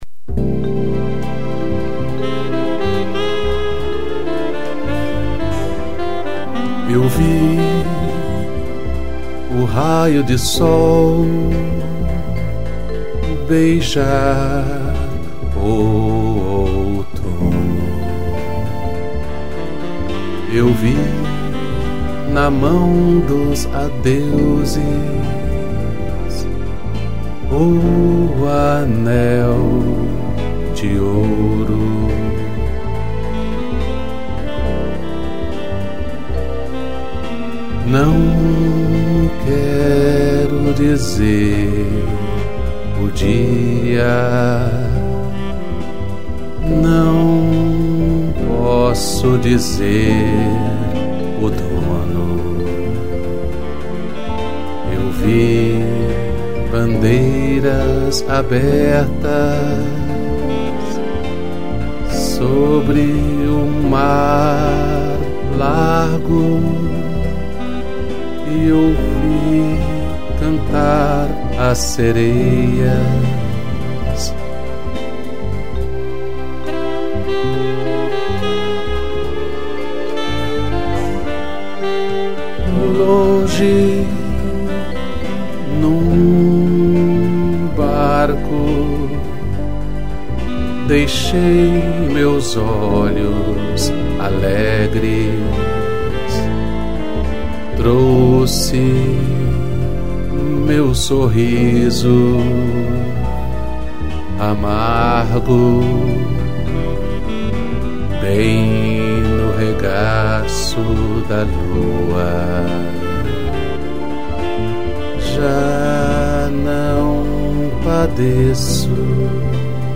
vibrafone, sax e strings